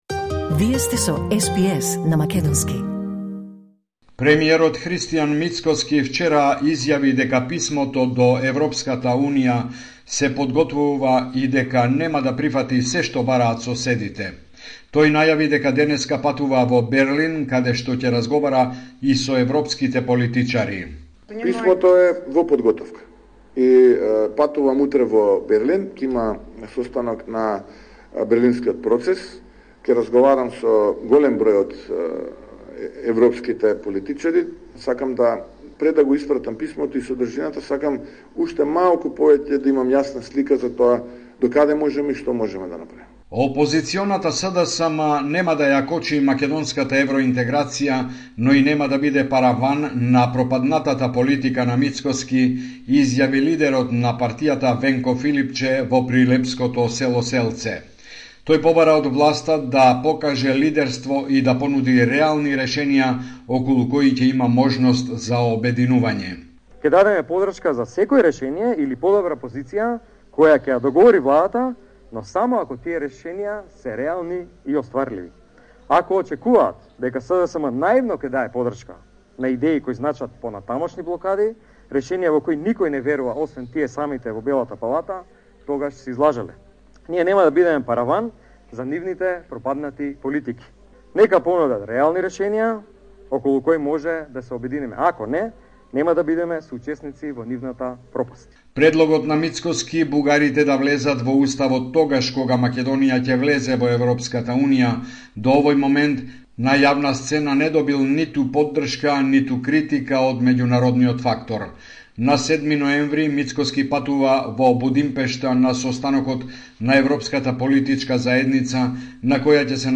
Homeland Report in Macedonian 14 October 2024